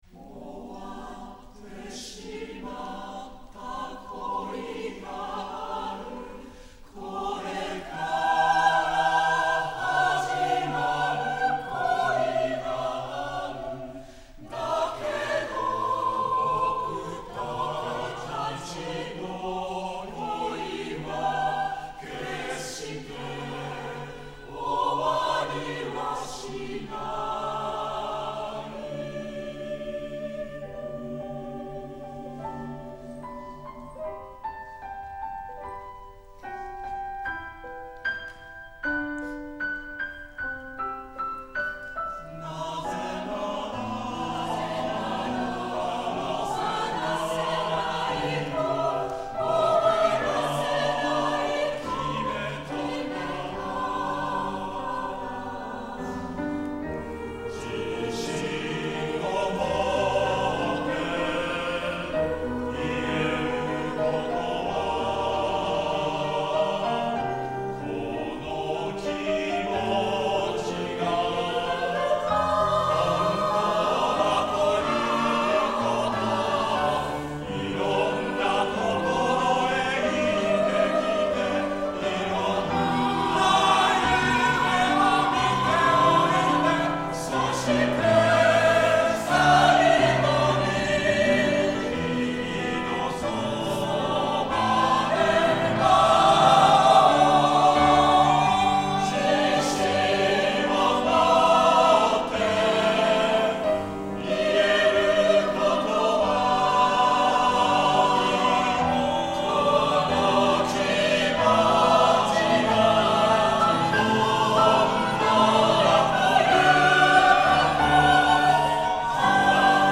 X 君のそばで会おう　上田真樹 混声合唱組曲「終わりのない歌」より 4:00 混声合唱団アプリス